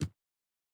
Land Step Stone B.wav